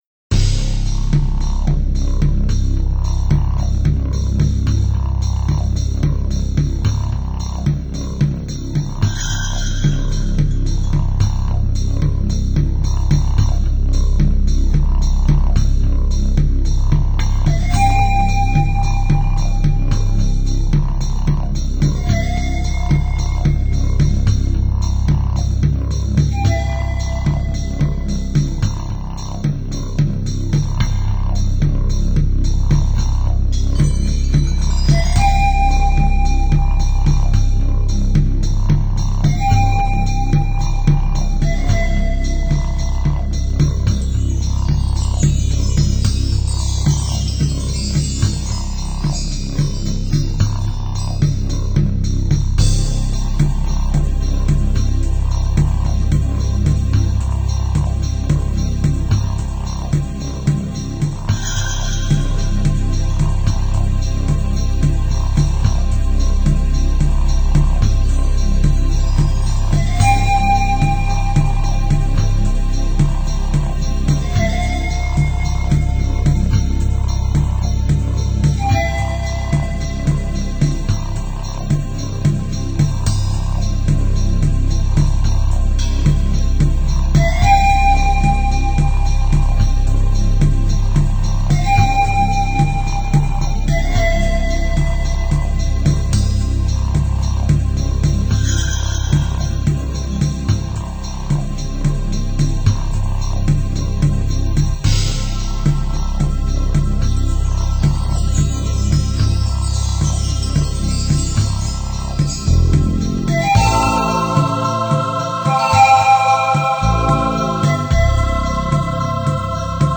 专辑语言：纯音乐
鼓声，又温柔的敲起来。
尺八，依然那样的柔美，在森林里悠悠回荡……